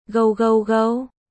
• Gâu gâu gâu
Gau-gau-gau.mp3